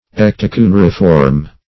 Search Result for " ectocuneriform" : The Collaborative International Dictionary of English v.0.48: Ectocuneriform \Ec`to*cu*ne"ri*form\, Ectocuniform \Ec`to*cu"ni*form\, n. [Ecto- + cuneiform, cuniform.]